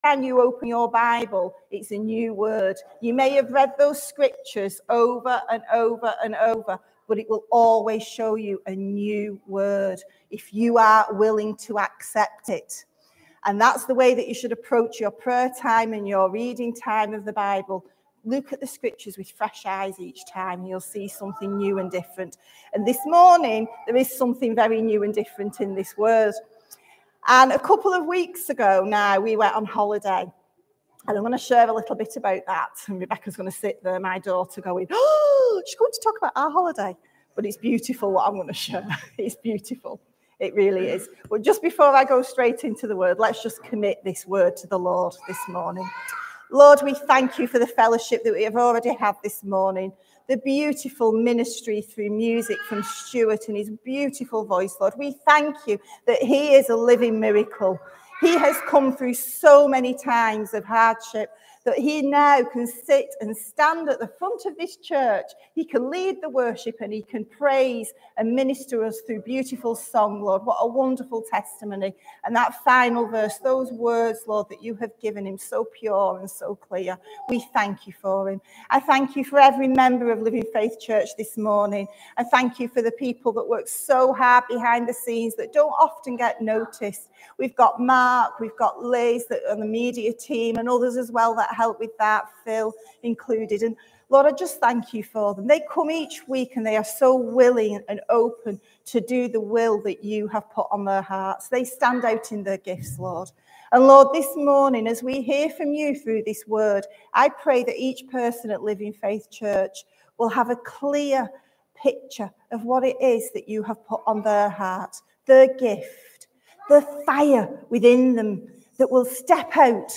Audio and video teachings from Living Faith Church